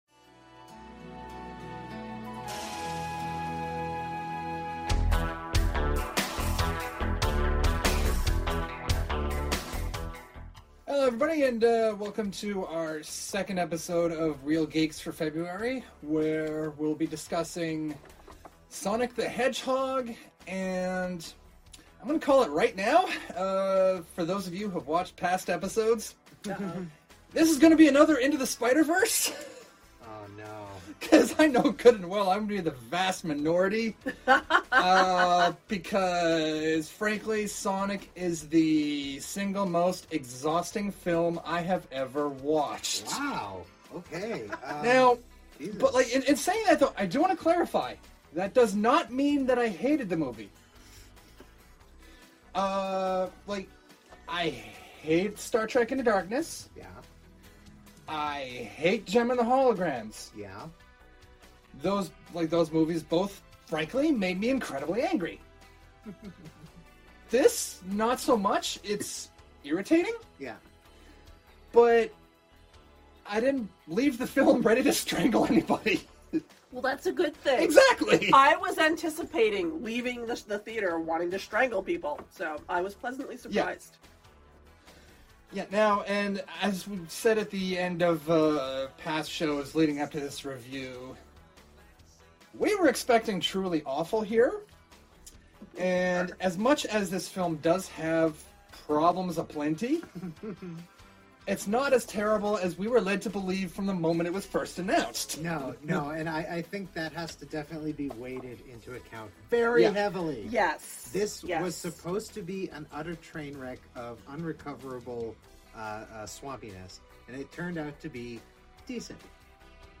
Originally recorded in Halifax, NS, Canada